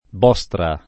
Bostra [ b 0S tra ]